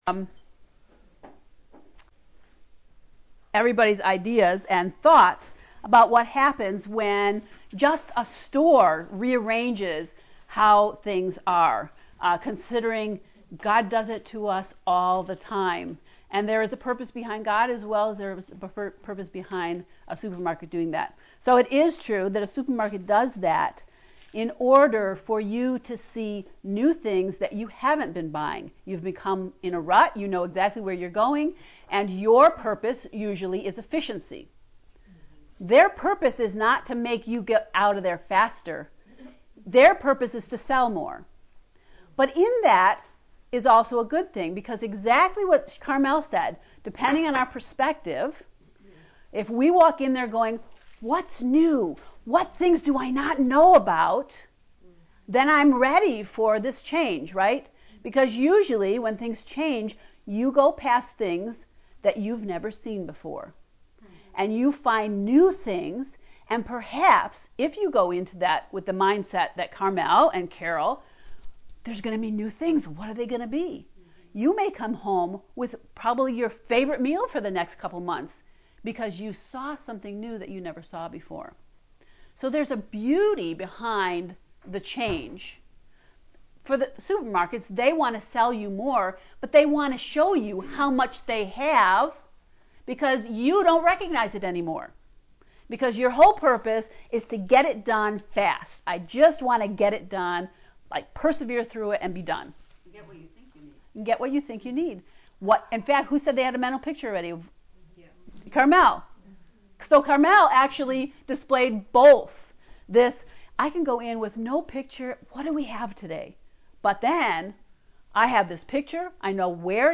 To listen to Hebrews lesson 3 lecture, “Master Builder Needed”, click here:
heb-lecture-3.mp3